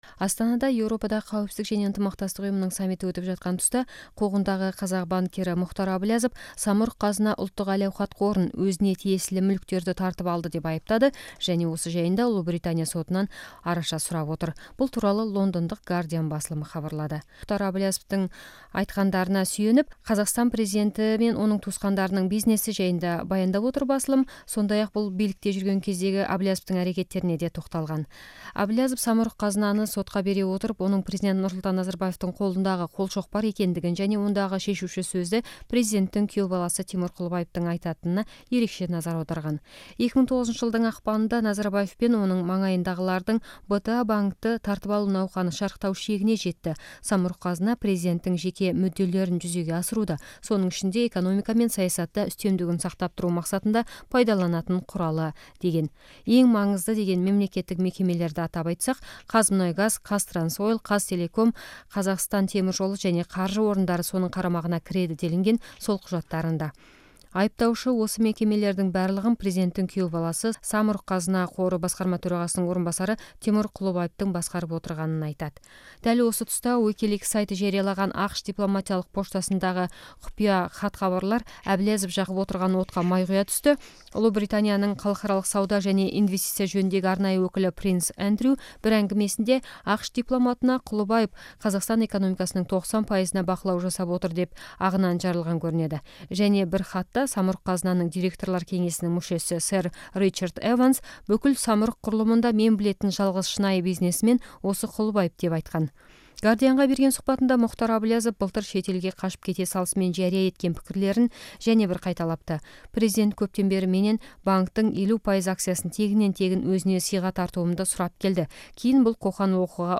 Қуғындағы бизнесмен Әблязовтың сұқбатында айтылғандарды тыңдаңыз